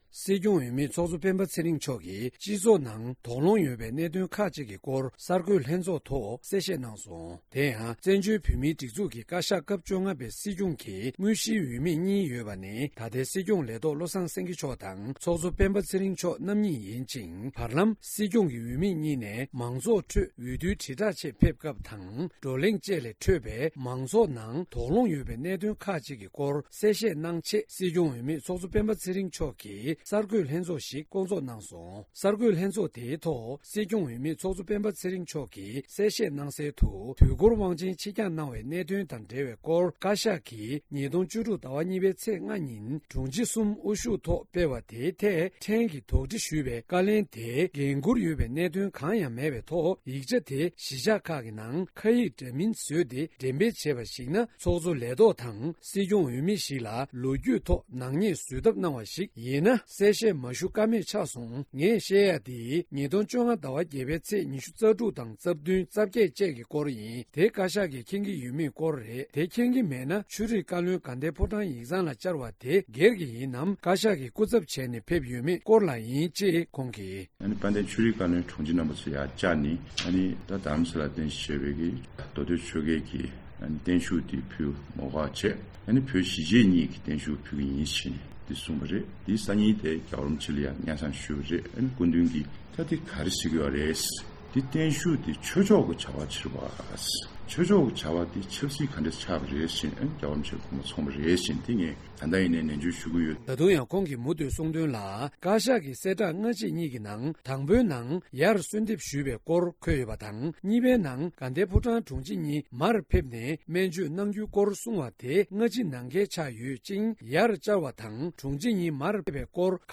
སྤེན་པ་ཚེ་རིང་མཆོག་གི་གསར་འགོད་གསལ་བསྒྲགས།
སྒྲ་ལྡན་གསར་འགྱུར།